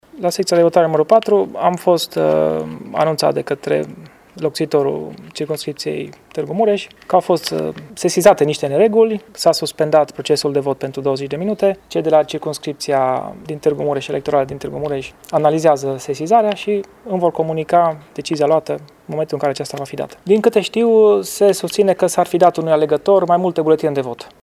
Președintele Biroului Electoral Județean Mureș, judecătorul Ioan Eugen Maier: